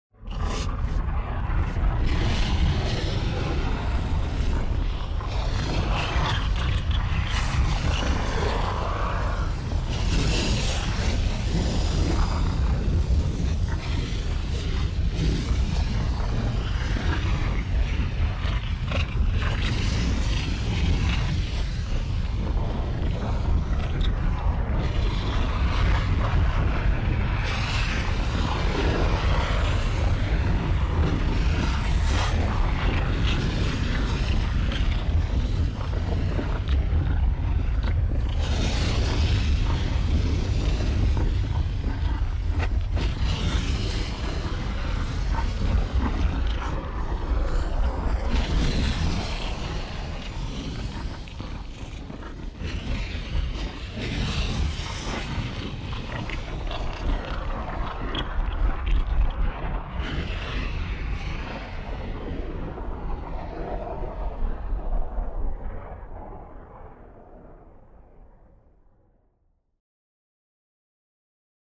Звуки извержения вулкана
• Качество: высокое
Бульканье раскаленной лавы